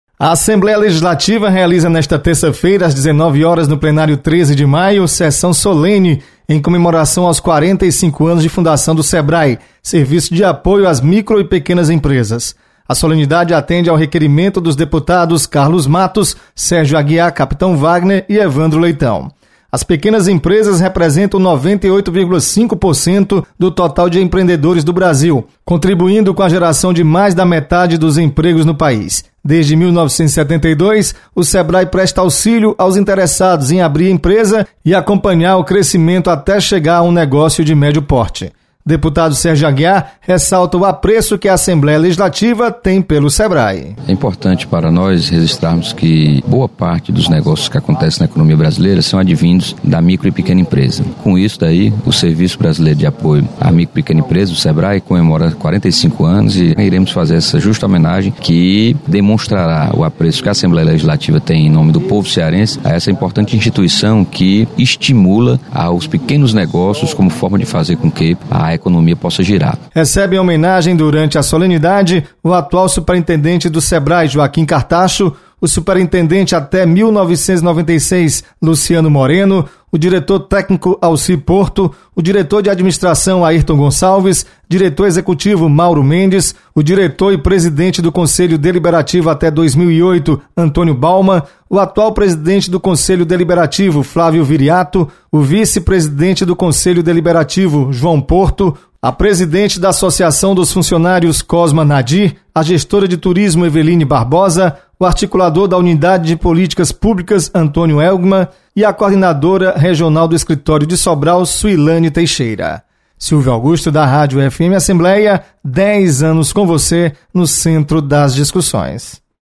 Solenidade